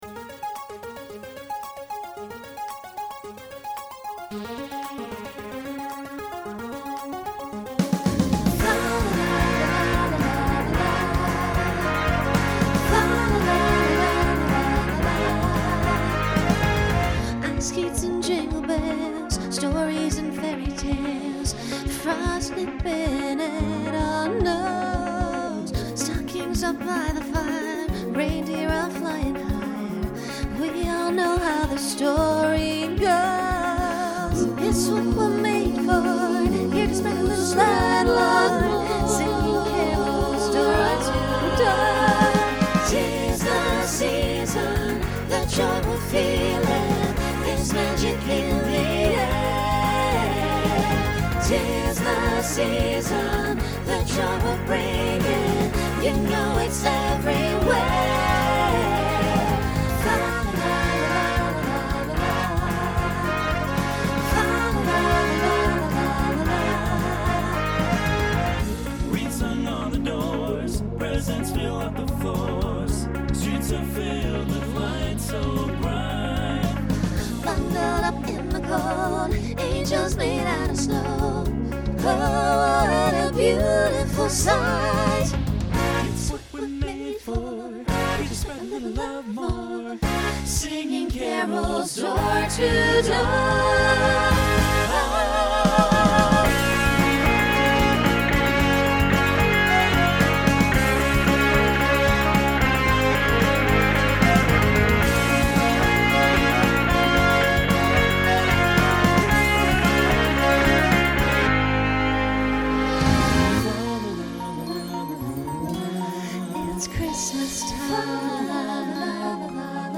Holiday , Pop/Dance Instrumental combo
Voicing SATB